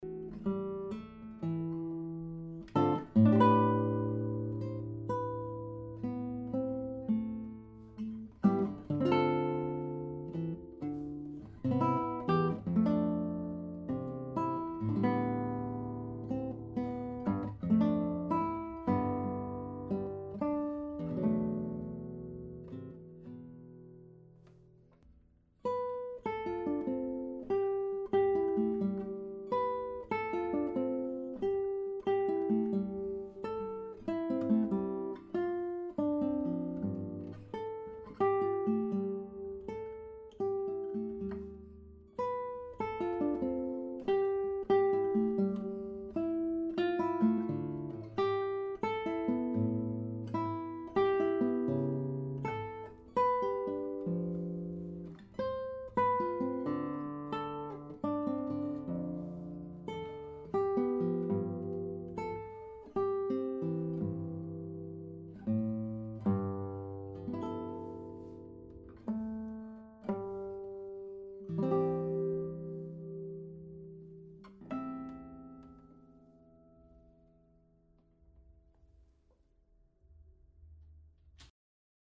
mikro rechts.mp3
Das Soundbeispiel ist unbearbeitet (ohne EQ und Hall)